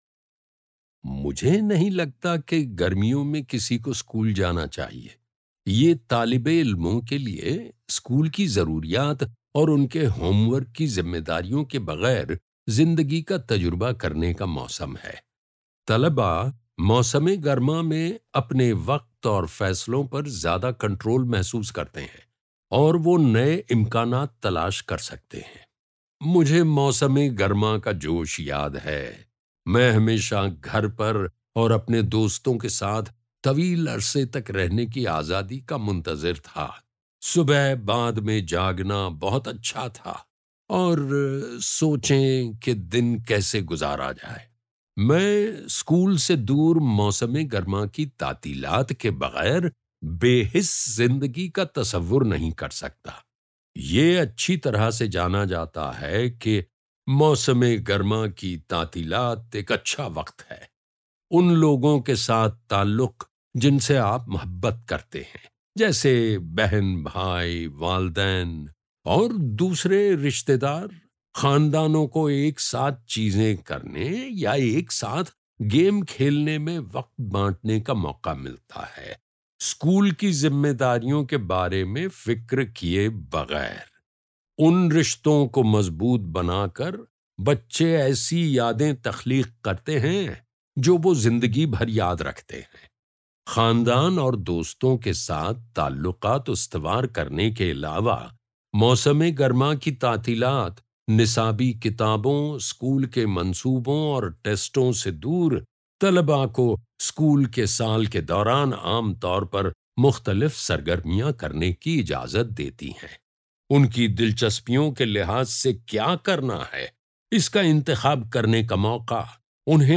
[Note: In the transcript below, ellipses indicate that the speaker paused.]